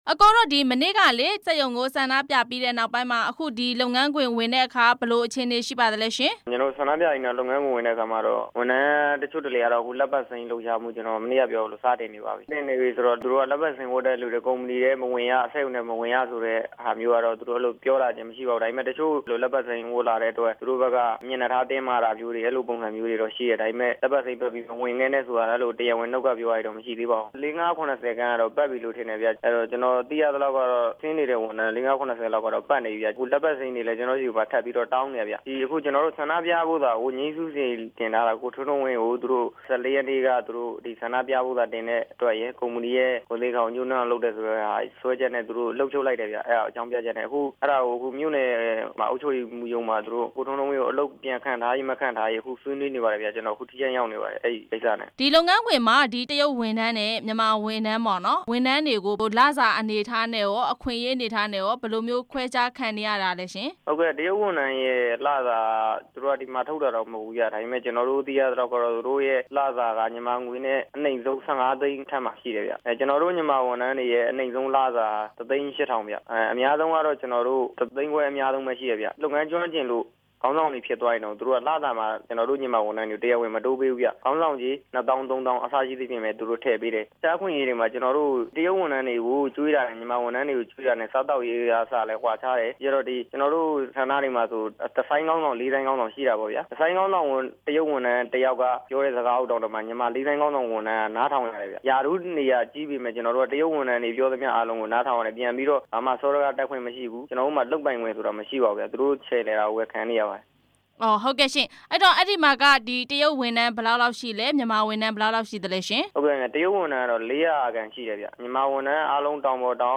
ဆန္ဒပြ တကောင်းနီကယ်စက်ရုံ အလုပ်သမားနဲ့ မေးမြန်းချက်